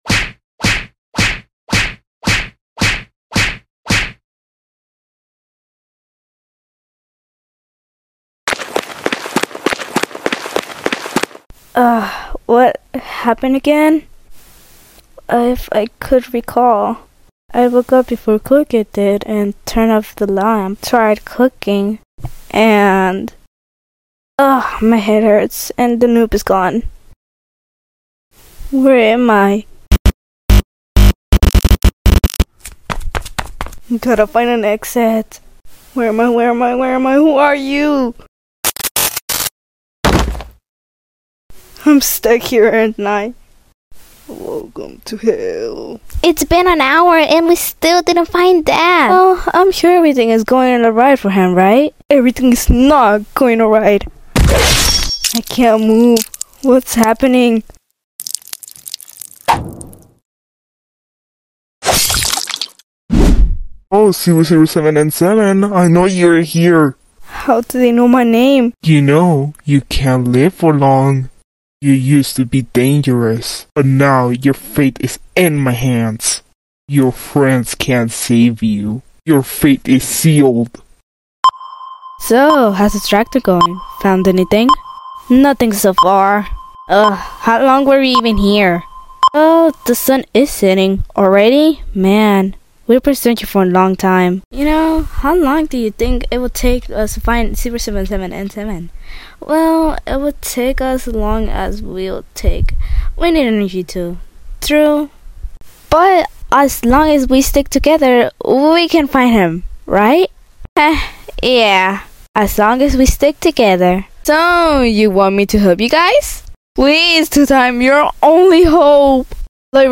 Voice acting comic! 007n7 missing